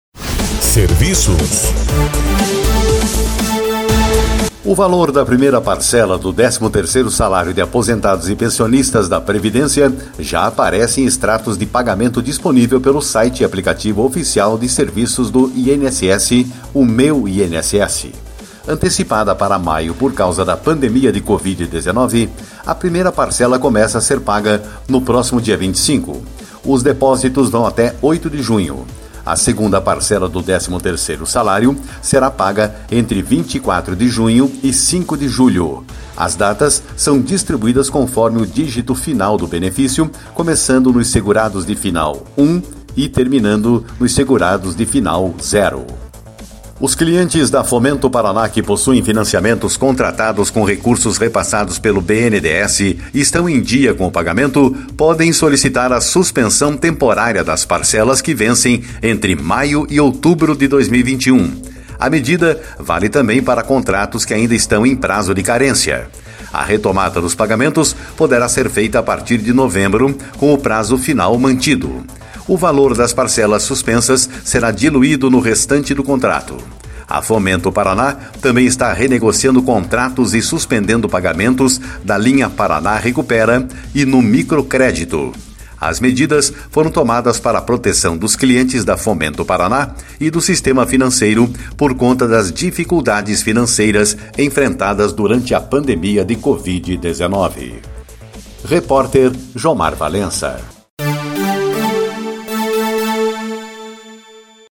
Fomento Paraná suspende pagamento de empréstimos com recursos do BNDES.// Mais notícias no boletim de serviços